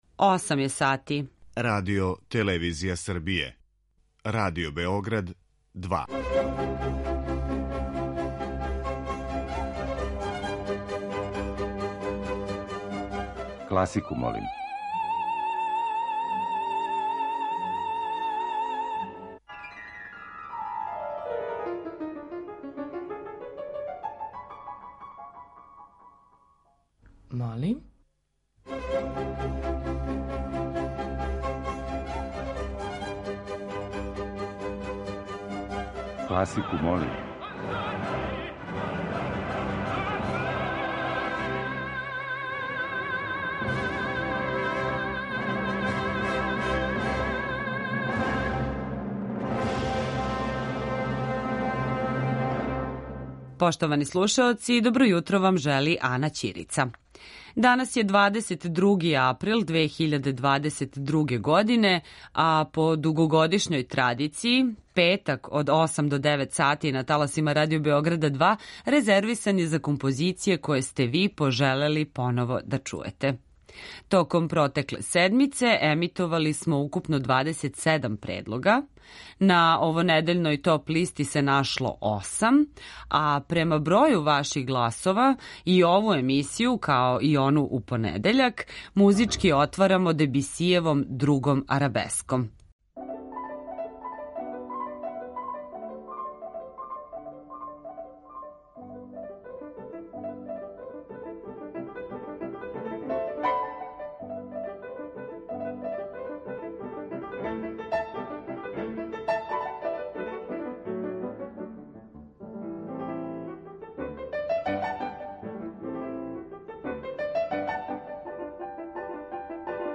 Композиције по избору слушалаца